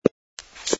sfx_fturn_male02.wav